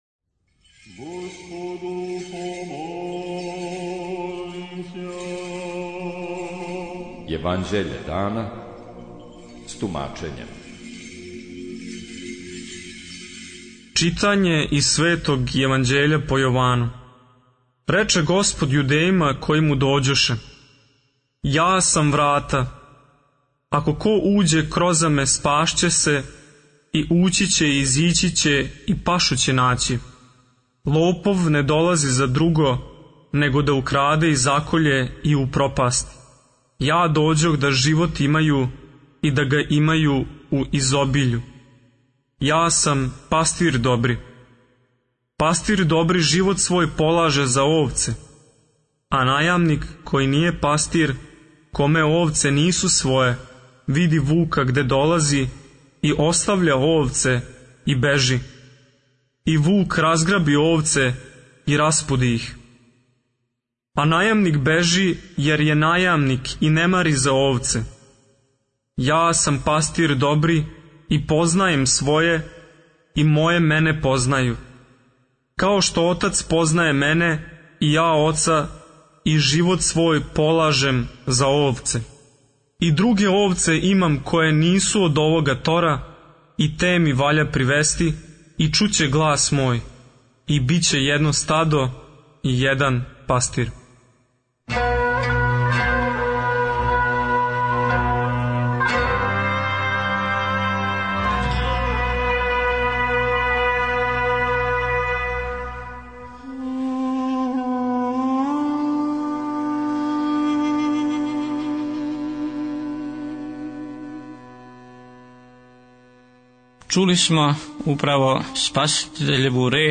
Читање Светог Јеванђеља по Матеју за дан 21.03.2026. Зачало 16.